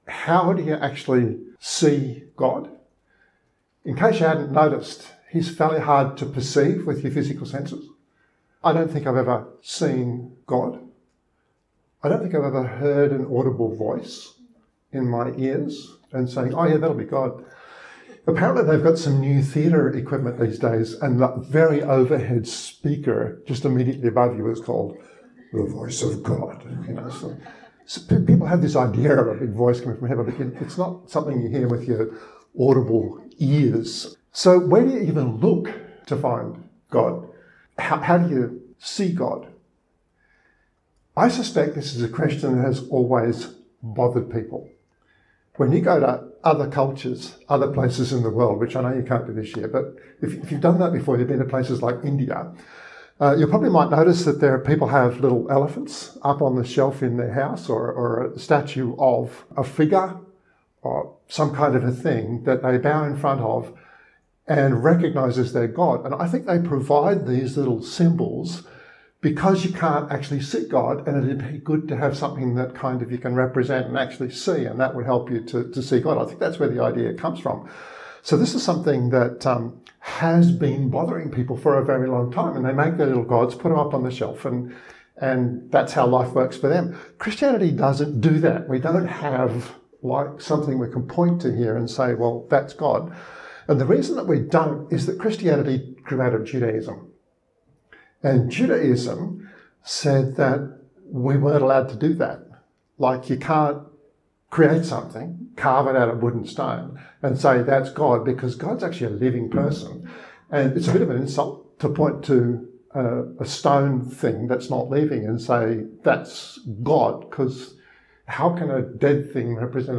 Spoken at Riverview Joondalup, 19 September 2021, it draws from this text: Continue reading “How do you find God?